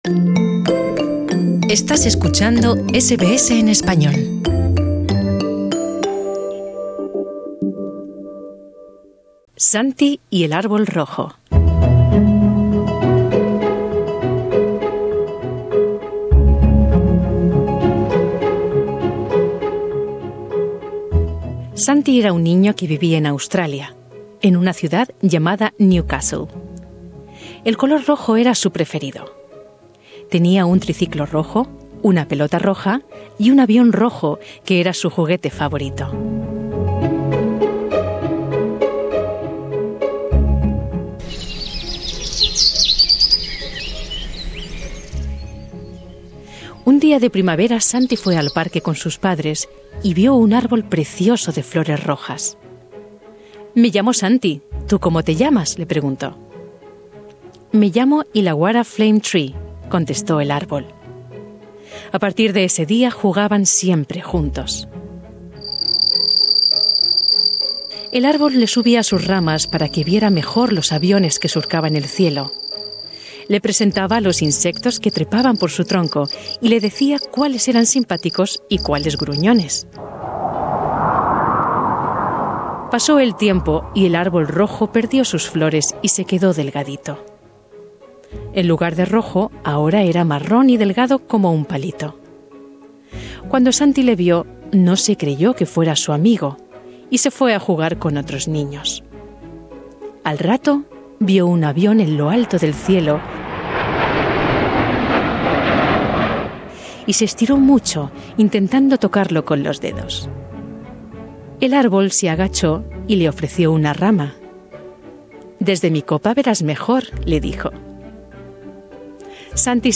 Una narración para disfrutar con los pequeños de la familia y en español, producida por Radio SBS Spanish.